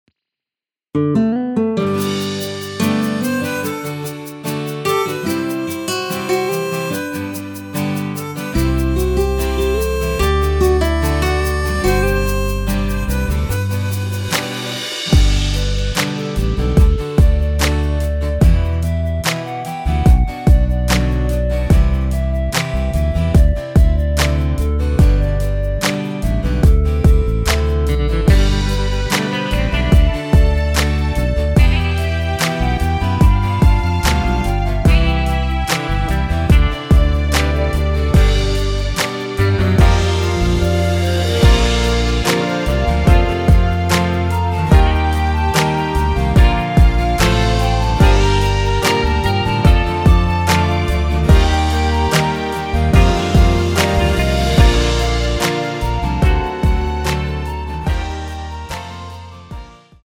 원키에서(-2)내린 멜로디 포힘된 MR입니다.(미리듣기 확인)
멜로디 MR이란
앞부분30초, 뒷부분30초씩 편집해서 올려 드리고 있습니다.
중간에 음이 끈어지고 다시 나오는 이유는